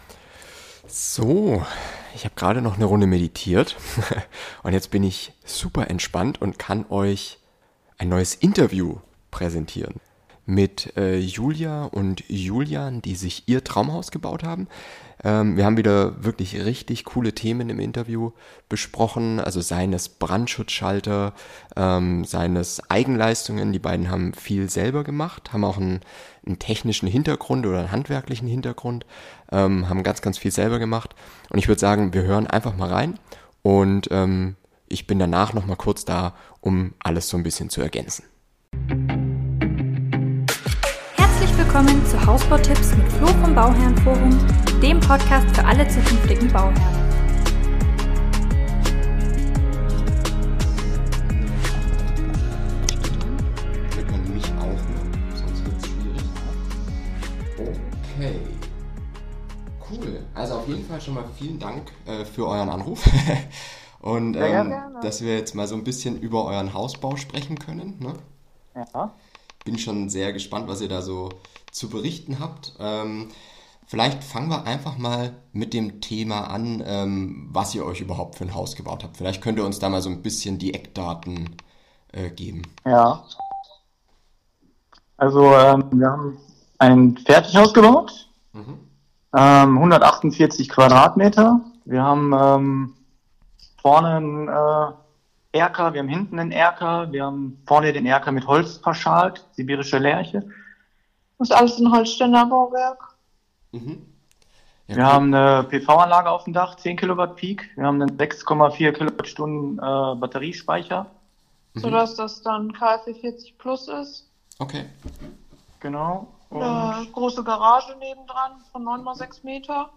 Heute gibt`s die nächste Interview Folge